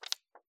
Turning Books Pages 52.wav